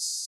Open Hats